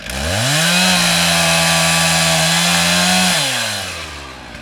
CHAINSAW.mp3